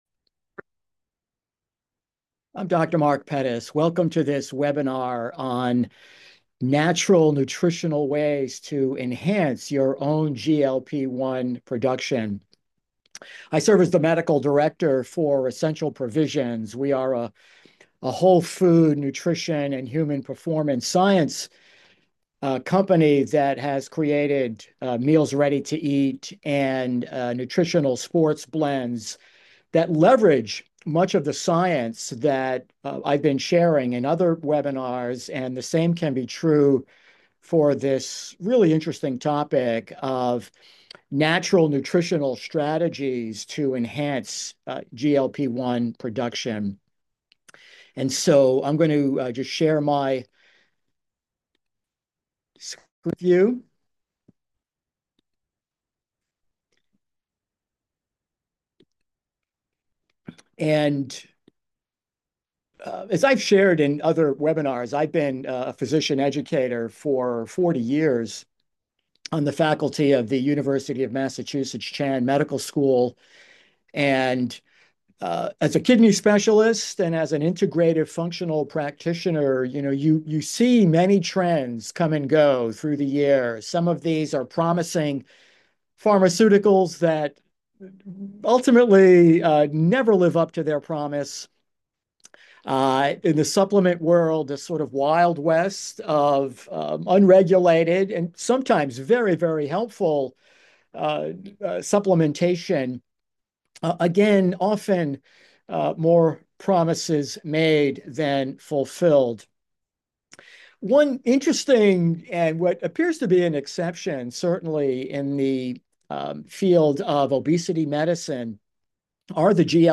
EP-Webinar-GLP-1.mp3